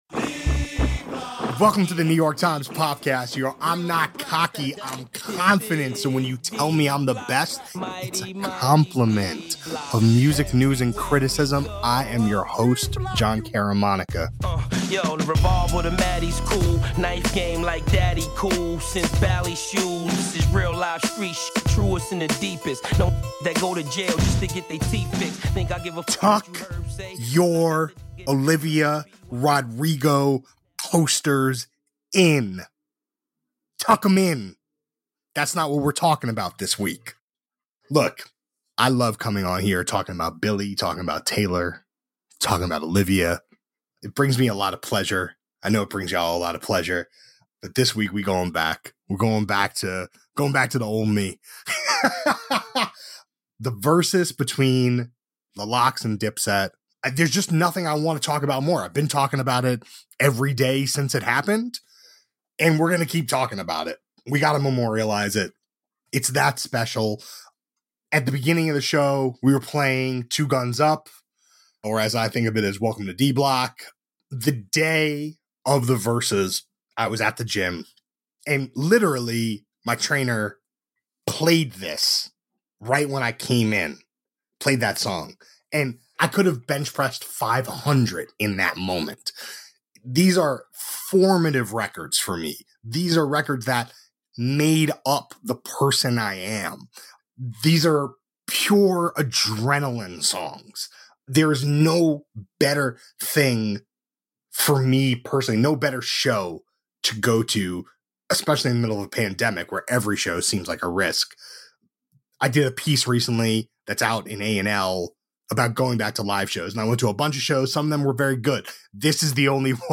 A conversation about New York hip-hop in the 1990s and early 2000s, and a night that brought the spirit of battle back to the pandemic-era staple.